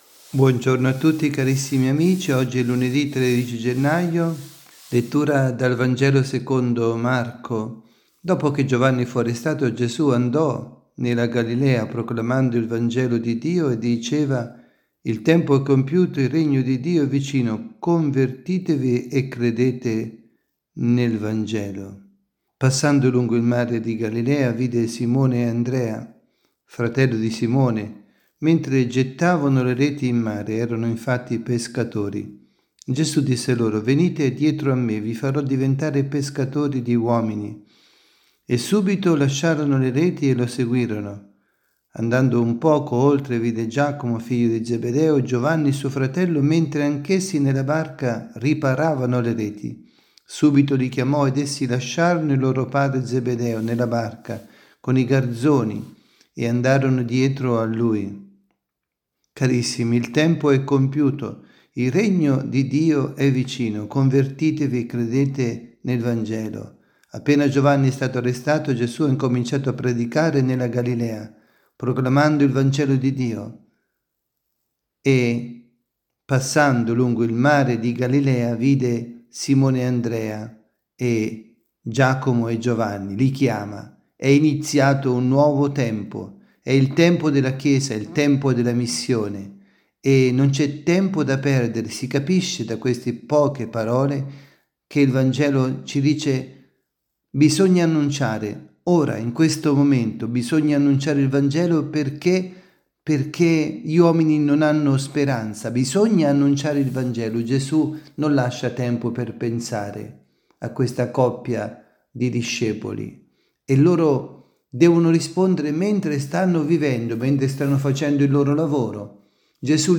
Catechesi
dalla Basilica di San Nicola – Tolentino
2025-01-13_Lunedi_pMG_Catechesi_dalla_Basilica_di_San_Nicola_Tolentino.mp3